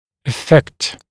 [ə’fekt][э’фэкт]поражать, влиять, воздействовать